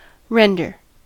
render: Wikimedia Commons US English Pronunciations
En-us-render.WAV